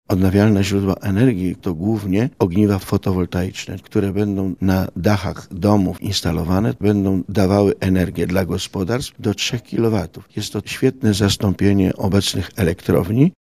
– Ekologiczne źródła energii to przyszłościowa inwestycja – mówi wójt gminy Sochaczew Mirosław Orliński.